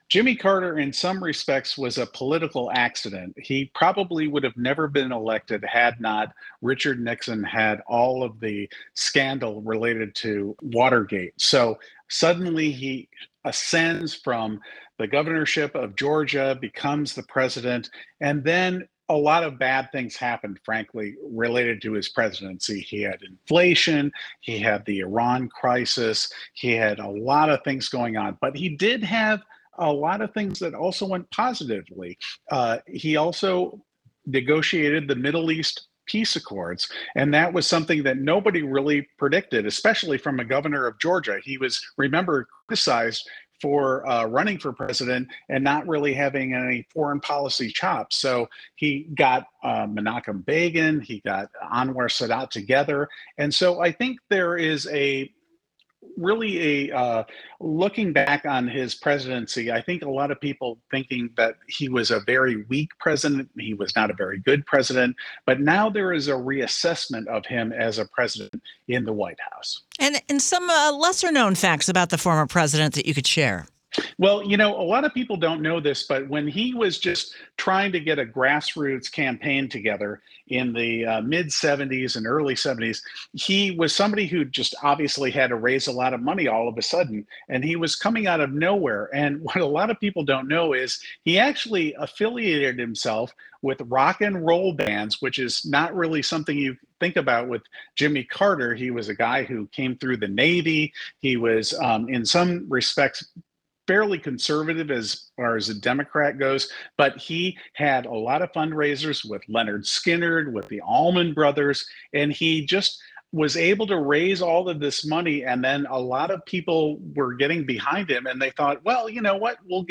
WTOP spoke with historians, political reporters and those who personally knew Carter as the nation mourned his death.